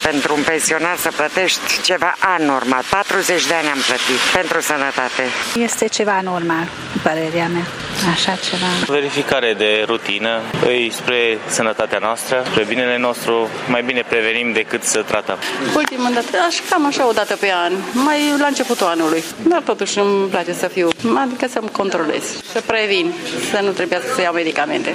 Orice plată în plus va crea reticență din partea populației pentru o măsură, chiar și preventivă, susțin târgumureșenii.